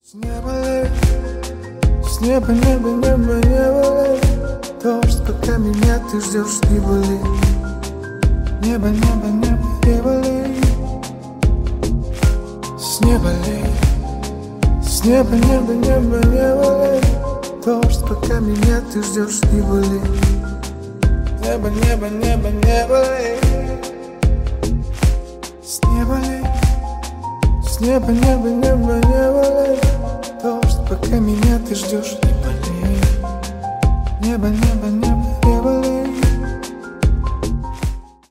Поп Музыка
спокойные # кавер